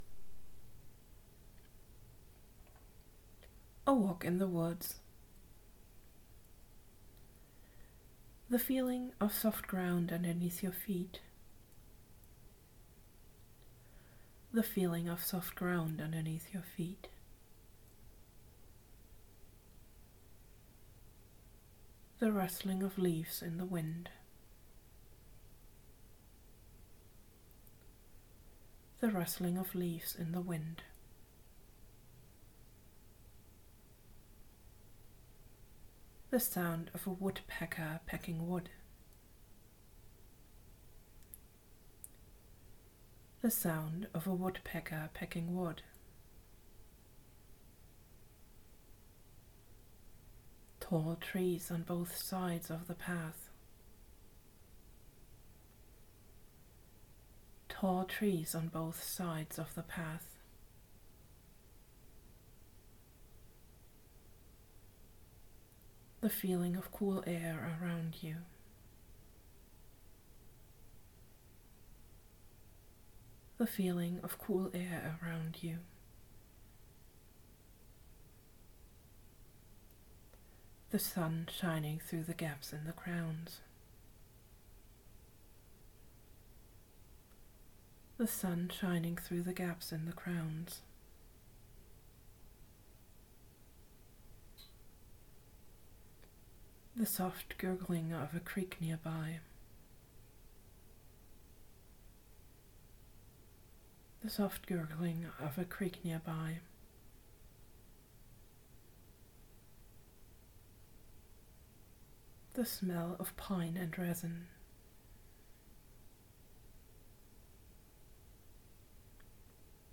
by on in english, guided imagery
In this short-form approach you get instructions to focus on imagining one sensory experience after the other. The instruction is repeated twice and then you are asked to shift to the next picture or sensory experience immediately. It is supposed to be so fast-paced that you have no time to come up with negative ideas or elaborate inner stories.